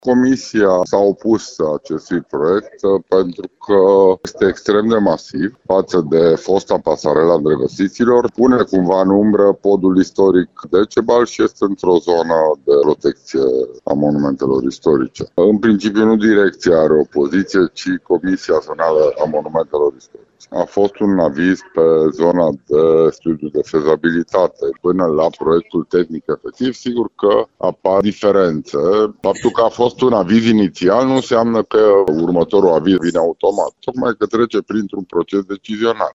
Directorul Direcției de Cultură Timiș, Sorin Predescu, susține că avizul este al Comisiei Zonale a Monumentelor Istorice, iar principala problemă este legată de dimensiunile noii pasarele.